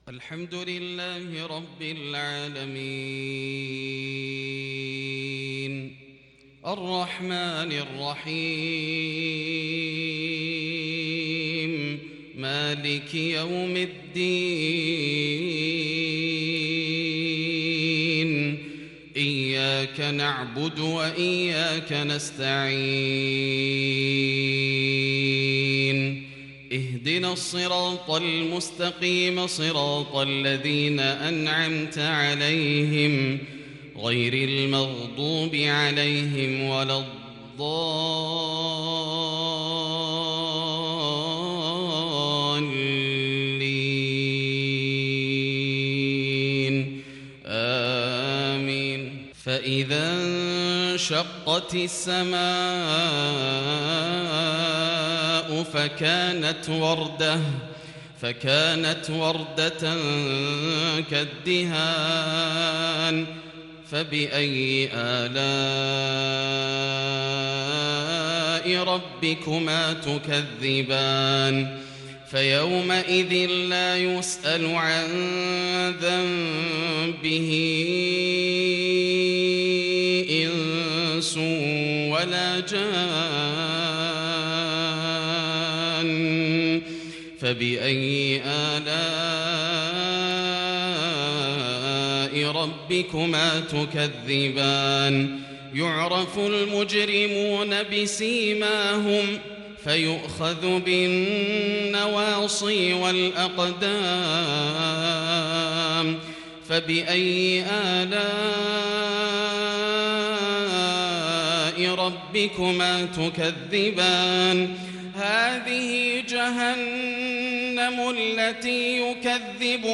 صلاة العشاء للشيخ ياسر الدوسري 5 ربيع الآخر 1442 هـ
تِلَاوَات الْحَرَمَيْن .